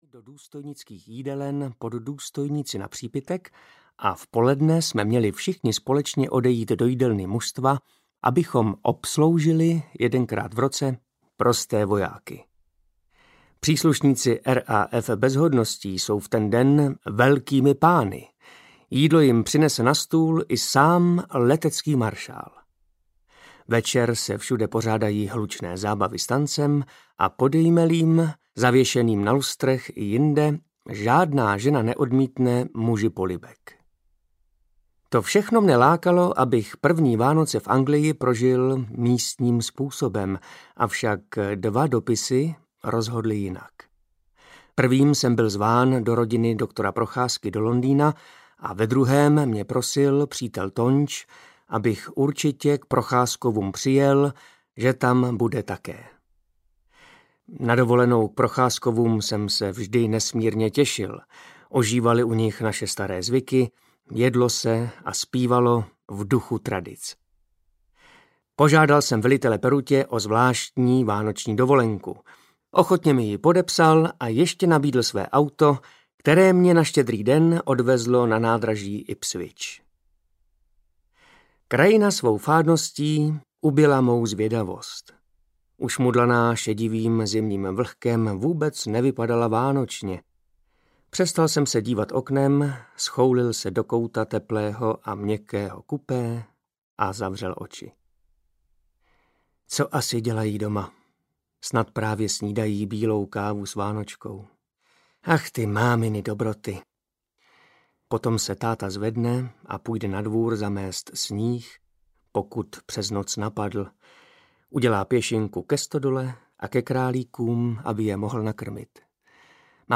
Adventní kalendář audiokniha
Ukázka z knihy
• InterpretTaťjana Medvecká, Jan Vlasák, Martha Issová, Martin Myšička